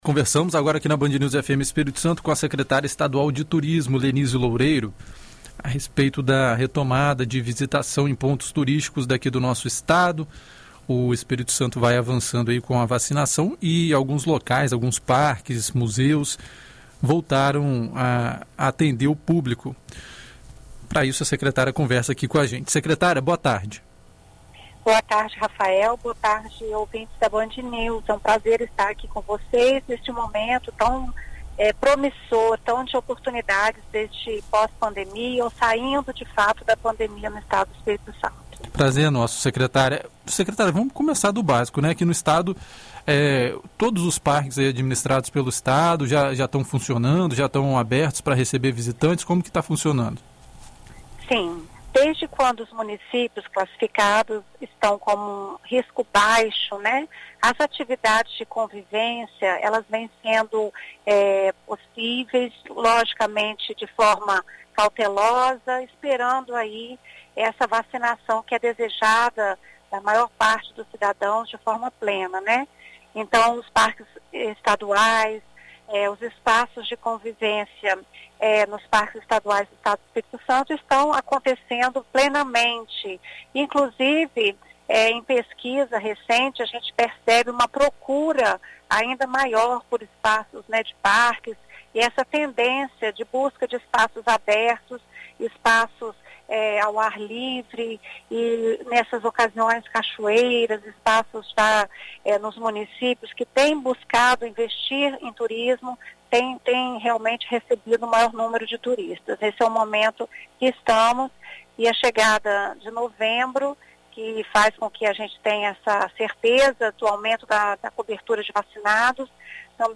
Em entrevista à BandNews FM Espírito Santo nesta sexta-feira (29), a secretária estadual do Turismo, Lenise Loureiro, comenta a retomada do turismo no Estado e as perspectivas para o setor.